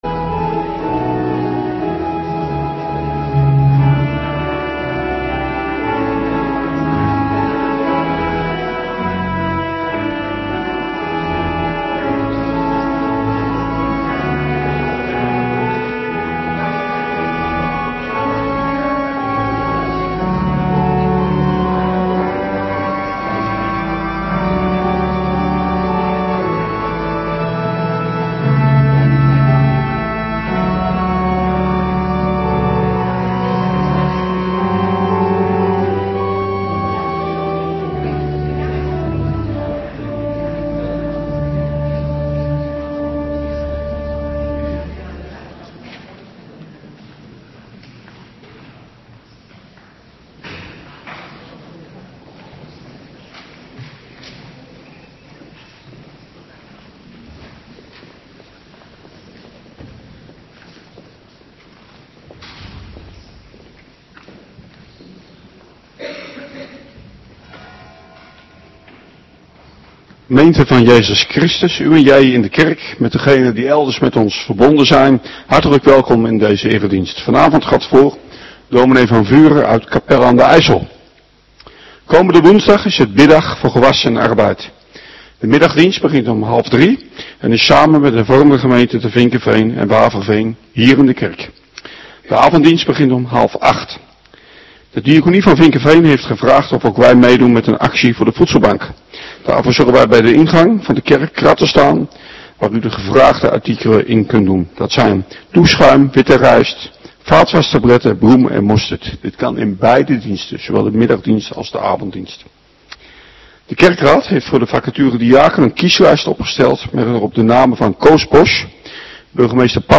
Avonddienst 8 maart 2026